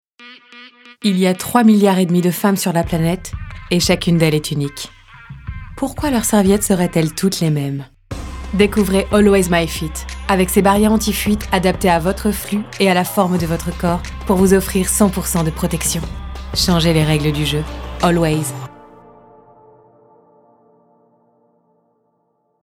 Voix off
25 - 60 ans - Mezzo-soprano